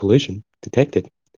collision-detected.wav